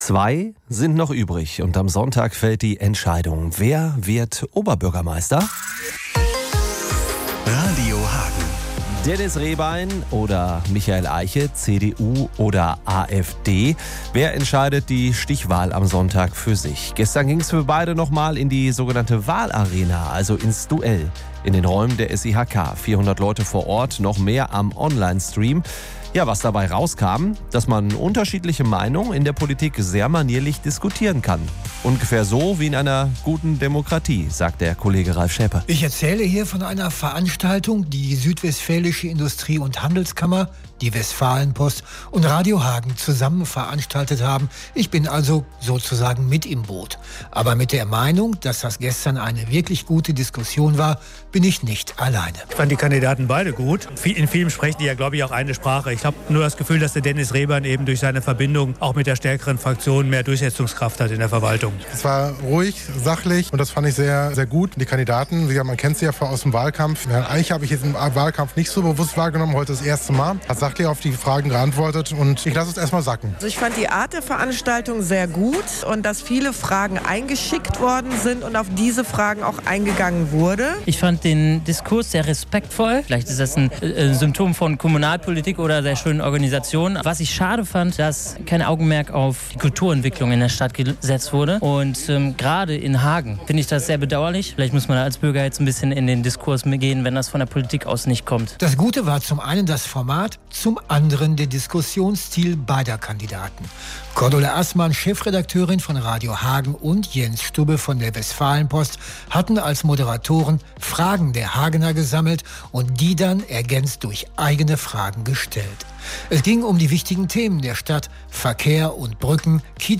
Zu dem Abend gibt es einen Radiobeitrag - und zwar hier: